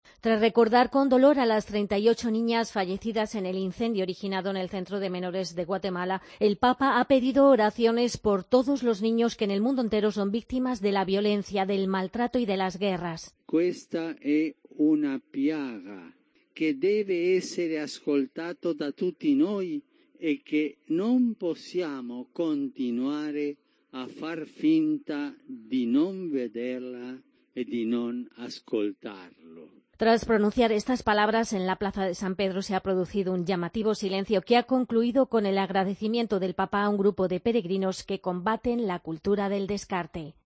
El Papa llama plaga a la explotación y violencia contra los niños. Crónica